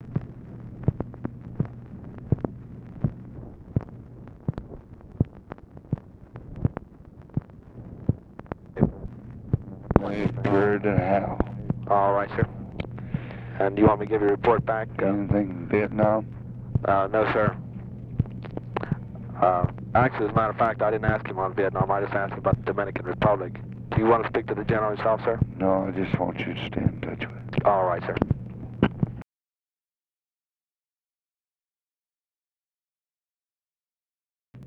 Conversation with WH SITUATION ROOM, May 2, 1965
Secret White House Tapes